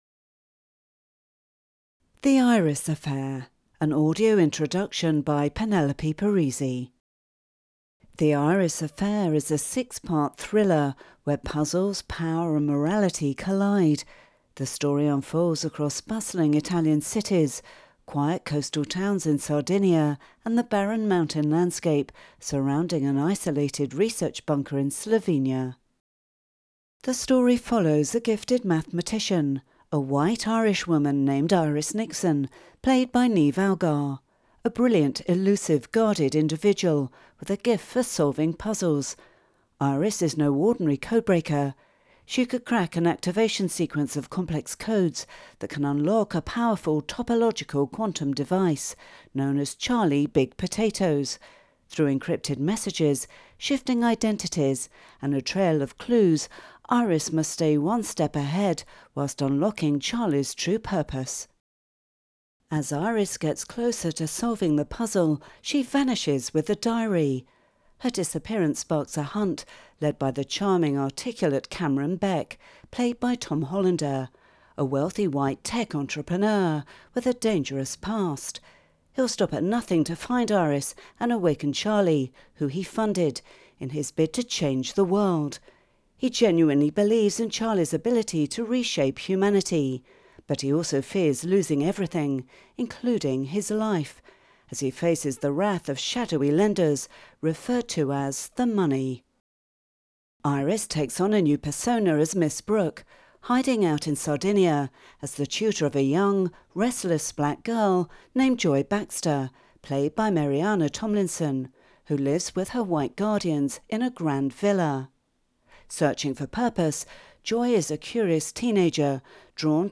The Iris Affair - Voiced AD Introduction